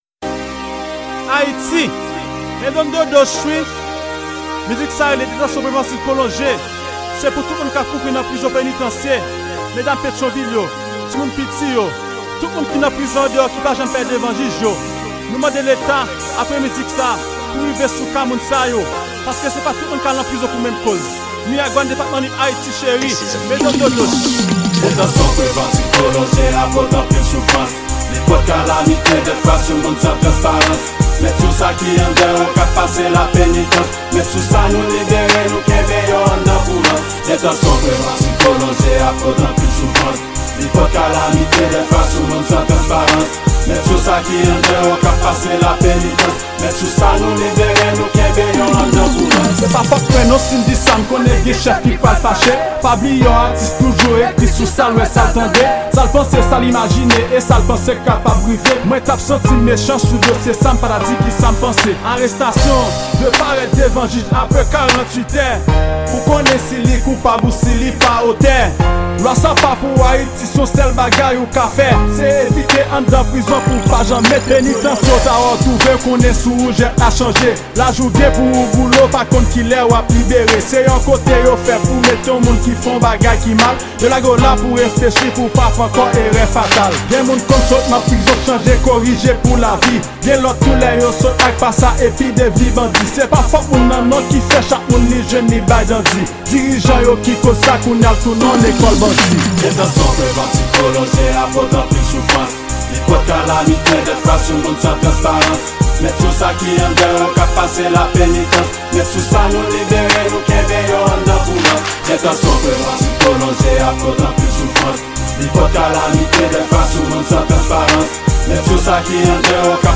Genre: Rap-Social.